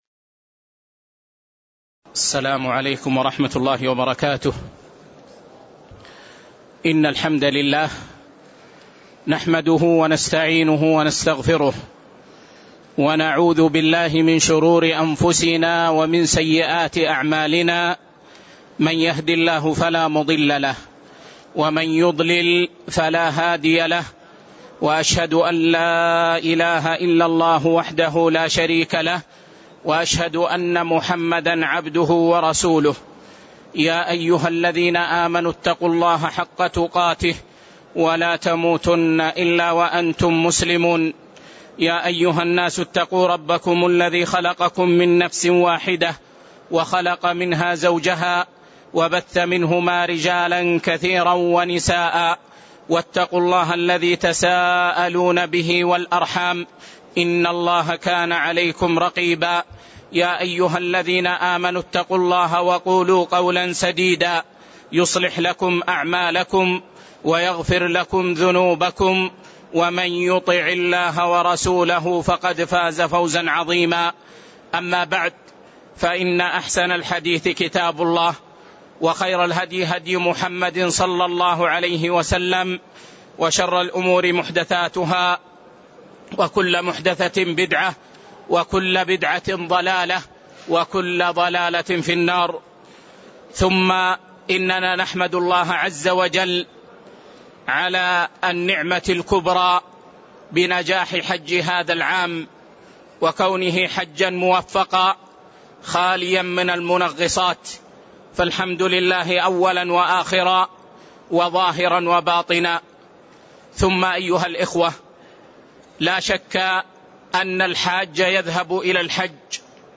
تاريخ النشر ١٦ ذو الحجة ١٤٣٤ هـ المكان: المسجد النبوي الشيخ